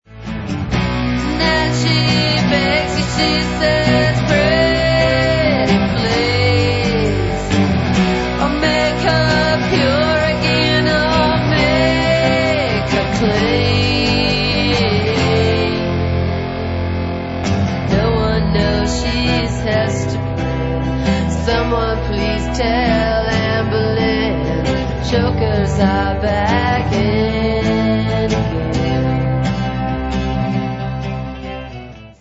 outtake